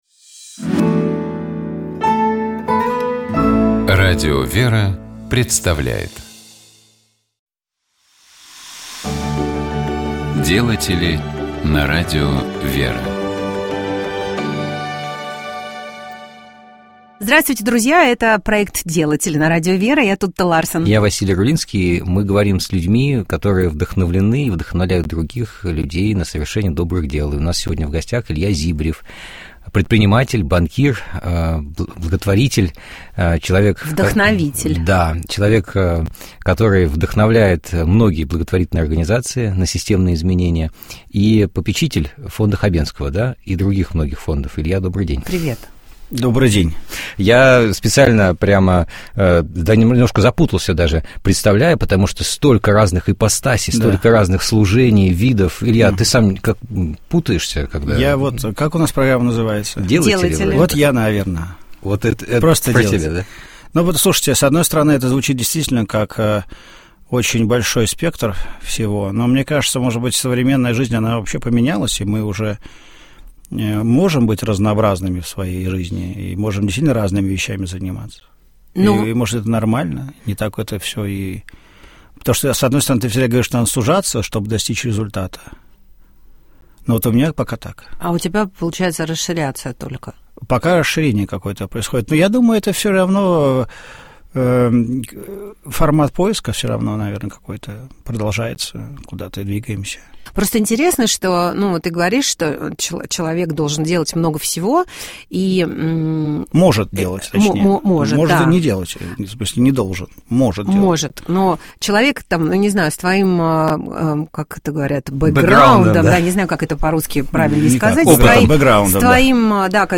У нас в гостях был предприниматель, меценат, банкир